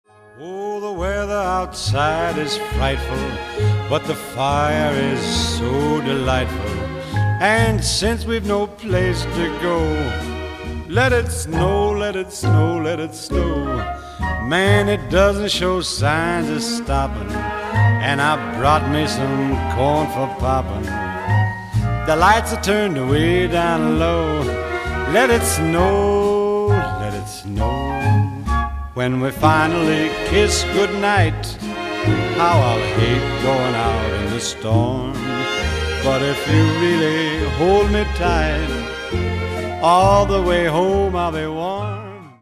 Рождественские
мужской голос